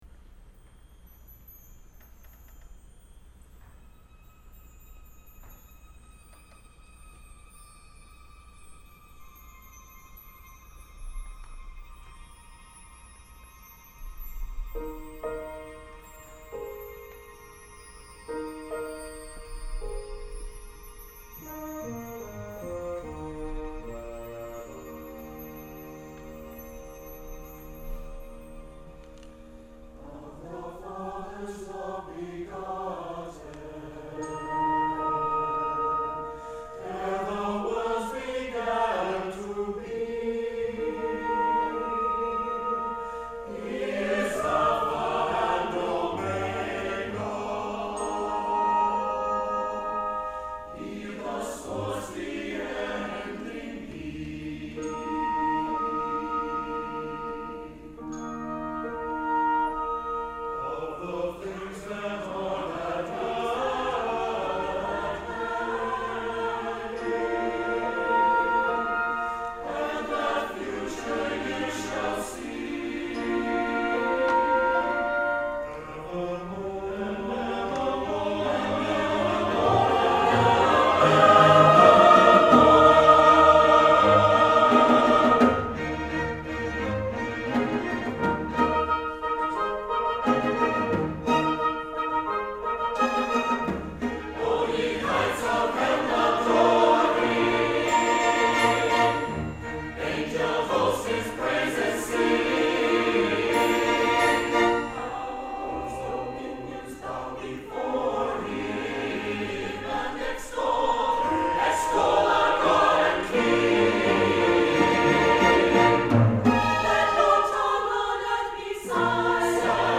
orchestra version, with piano substituting for brass